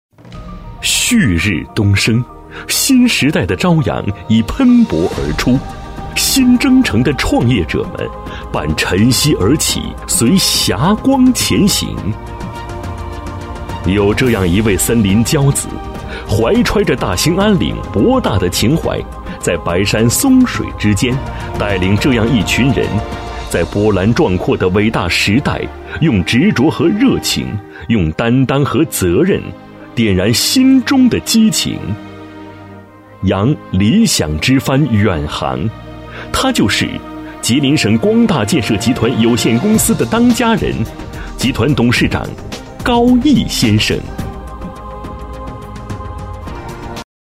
人物专题配音